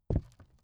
ES_Walk Wood Creaks 4.wav